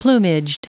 Prononciation du mot plumaged en anglais (fichier audio)
Prononciation du mot : plumaged